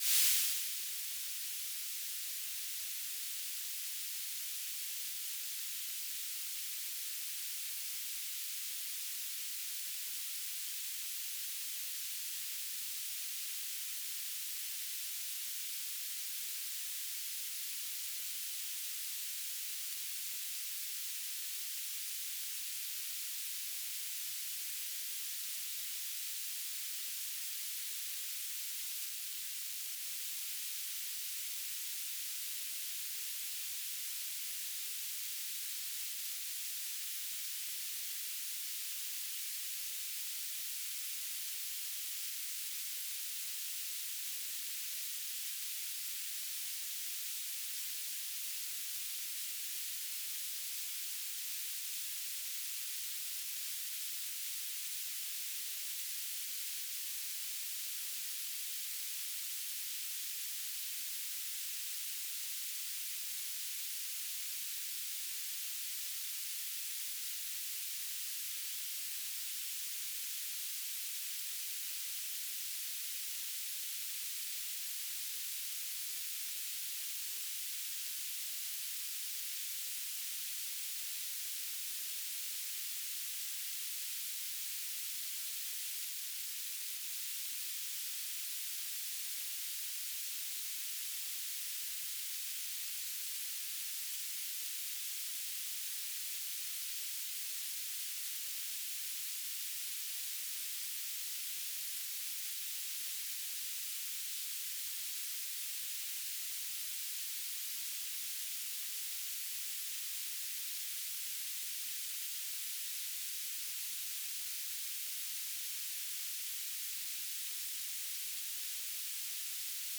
"transmitter_description": "Mode U BPSK9k6 TLM",
"transmitter_mode": "BPSK",